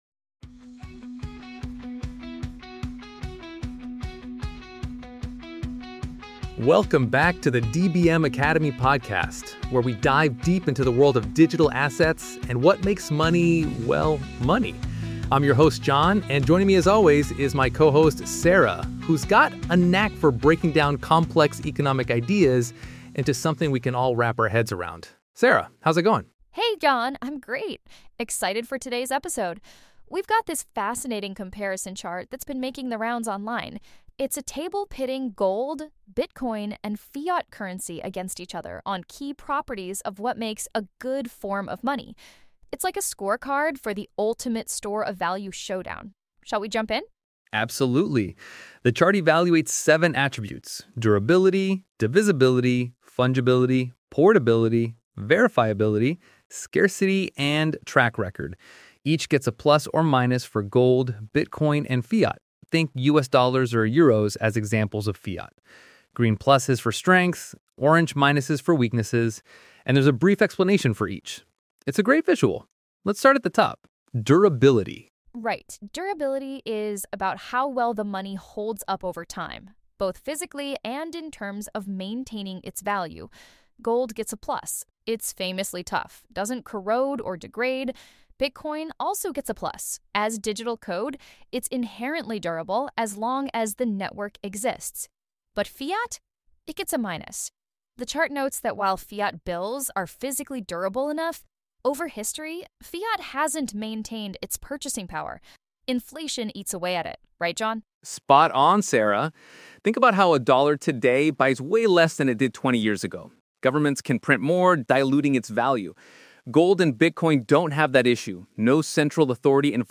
This podcast from DBM Academy features the hosts comparing gold, Bitcoin, and fiat currency across seven properties of money: durability, divisibility, fungibility, portability, verifiability, scarcity, and track record.